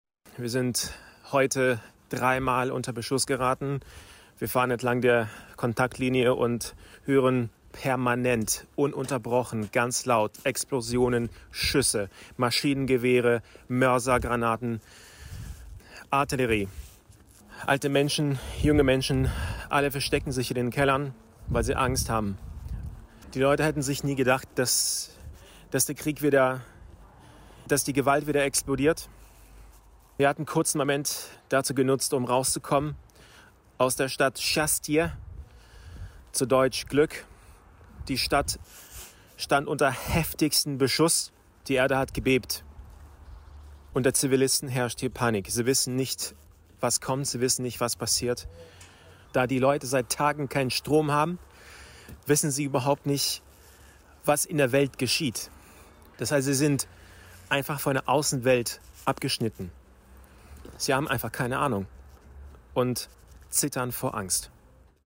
Augenzeugenbericht aus der Region Luhansk